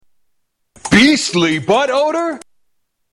Tags: Media Doc Bottoms Aspray Doc Bottoms Aspray Ads Doc Bottoms Aspray Commercial Body Deodorant